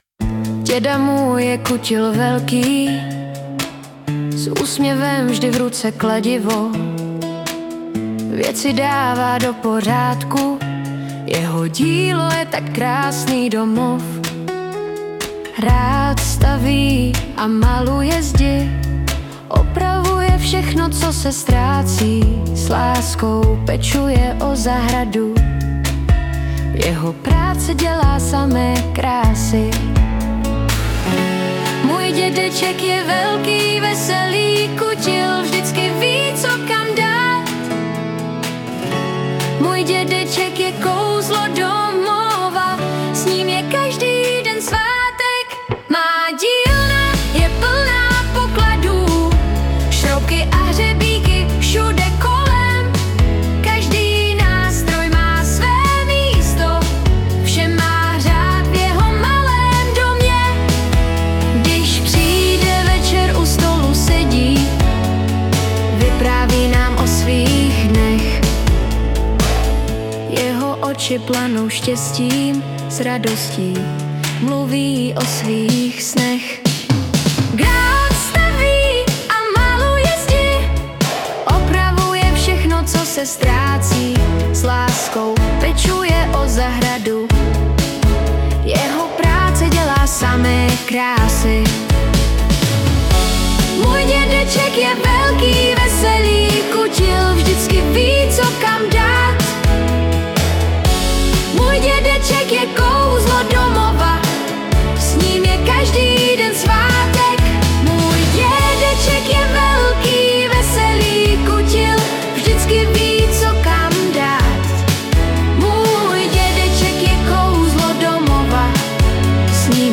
Tato písnička vznikla výhradně za pomoci AI. Do požadavku jsem zadal něco jako vytvoř popovou písničku o mém dědečkovi, který je velký kutil.